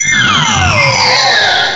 Cri de Lugulabre dans Pokémon Noir et Blanc.